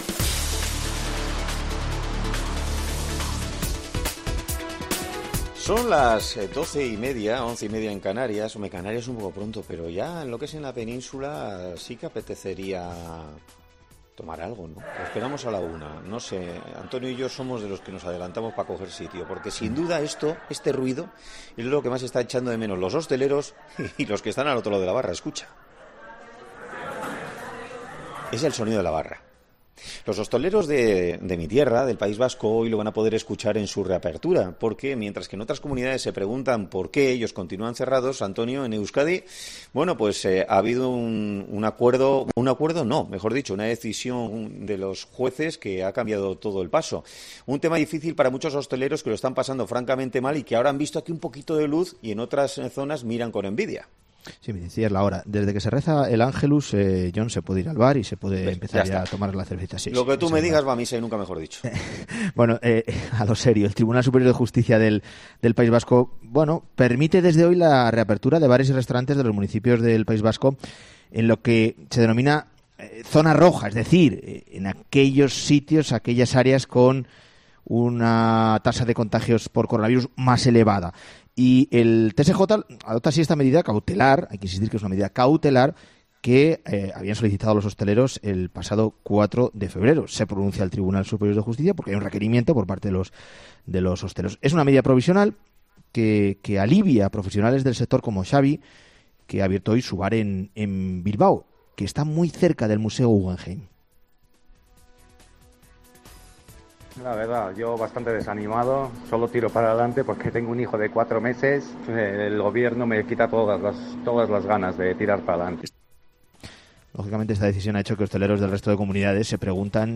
Con Carlos Herrera